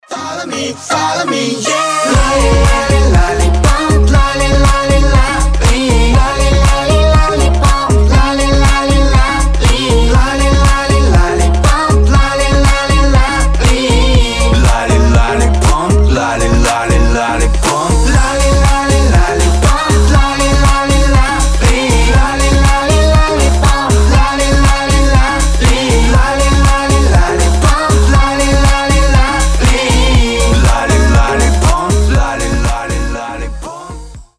женский вокал
dance
дуэт
club
красивый женский голос
женский и мужской вокал